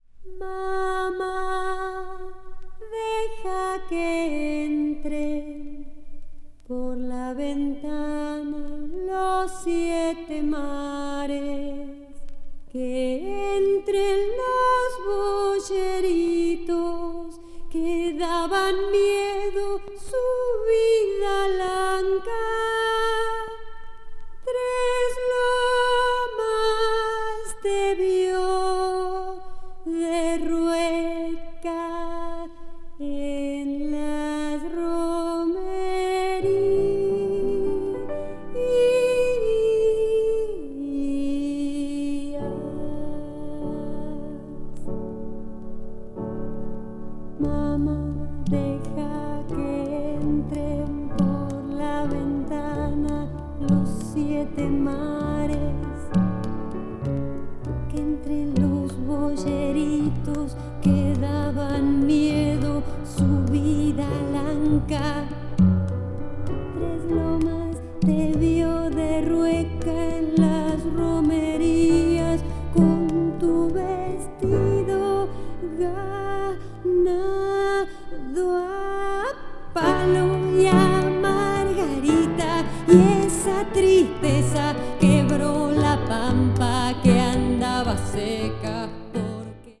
media : EX-/EX-(わずかにチリノイズが入る箇所あり,軽いプチノイズ数回あり)